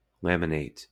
Ääntäminen
Southern England: IPA : /ˈlæmɪneɪt/